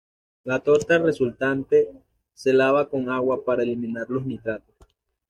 Pronounced as (IPA) /ˈtoɾta/